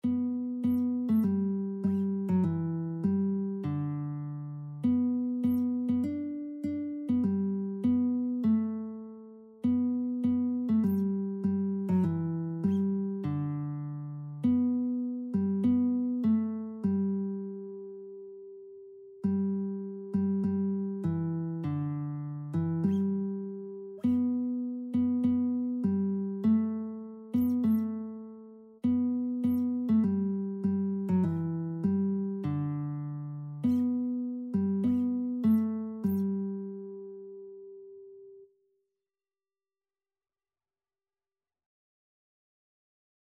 Christian Christian Lead Sheets Sheet Music Glory to His Name
4/4 (View more 4/4 Music)
G major (Sounding Pitch) (View more G major Music for Lead Sheets )
Classical (View more Classical Lead Sheets Music)